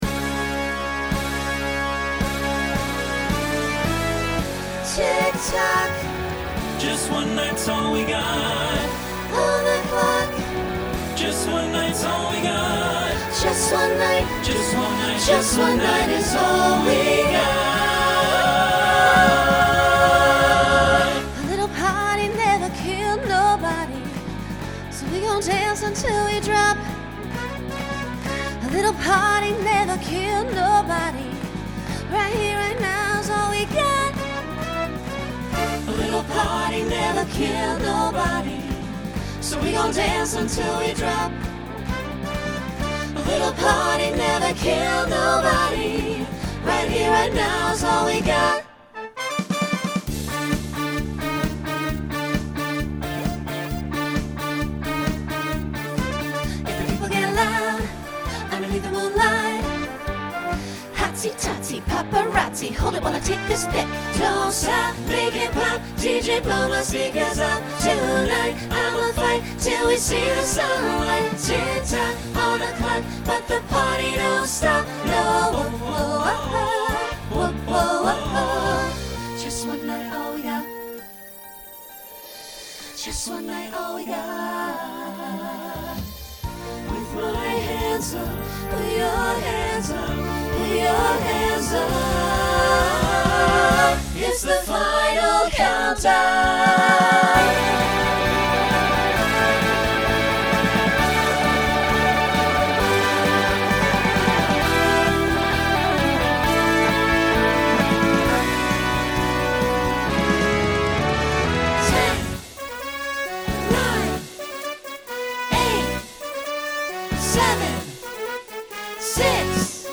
Genre Pop/Dance , Rock
Voicing SATB